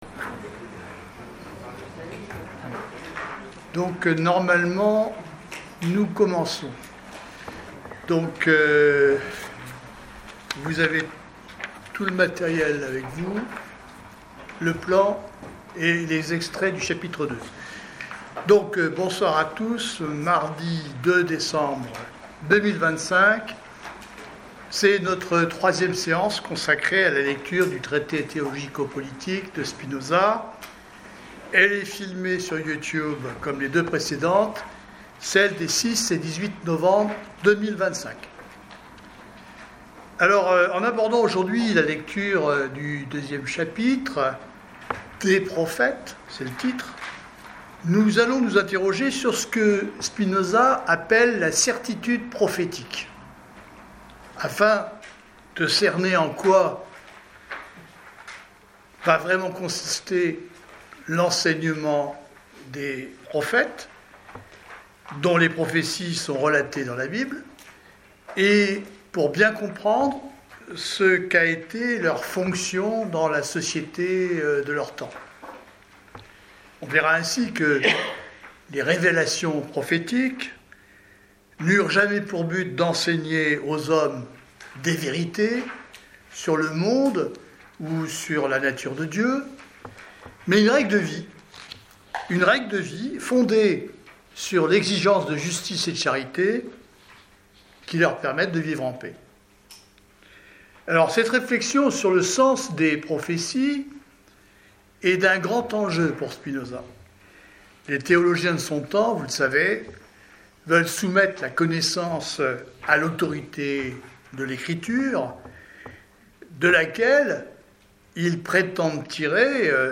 1/ Enregistrement de la séance du 02 décembre 2025